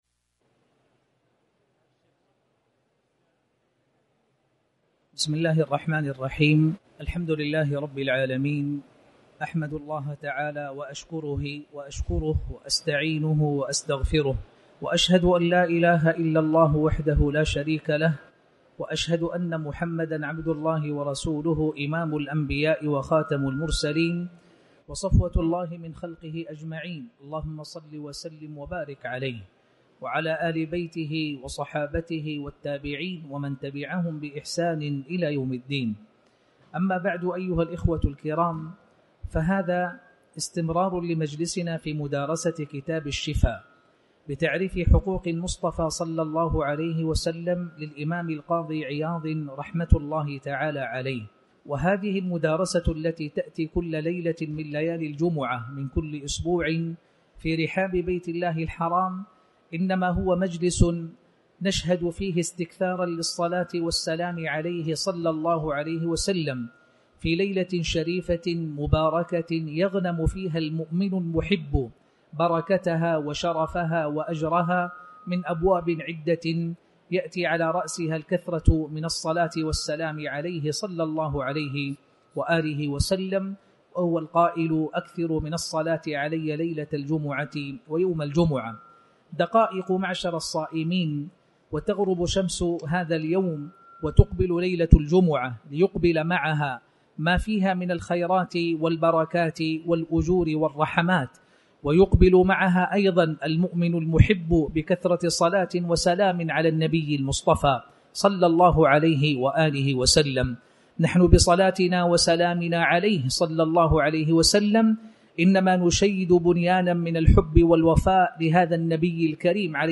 تاريخ النشر ١٥ رمضان ١٤٣٩ هـ المكان: المسجد الحرام الشيخ